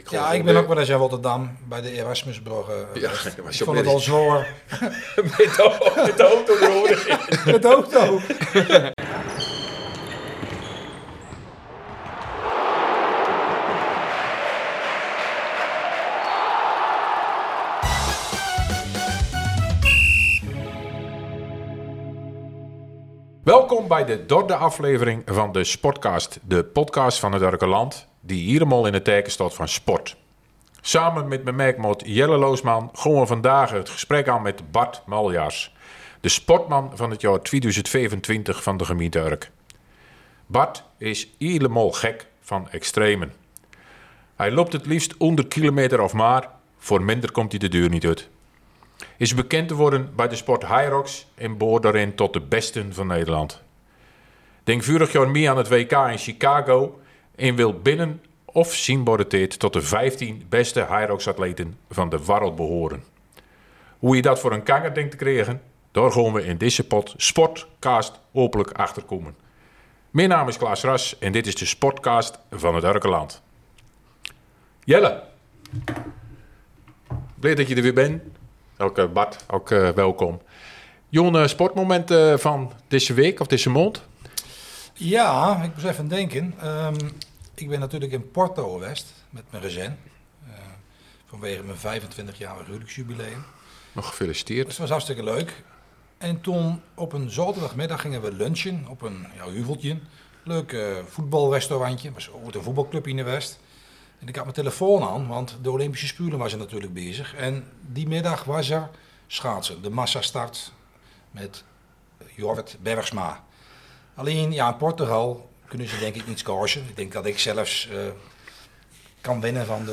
Luister naar interessante gesprekken met de inwoners over spraakmakende onderwerpen. Soms ontspannen aan de keukentafel, dan weer dicht op de huid, verrassend en vaak met een kwinkslag.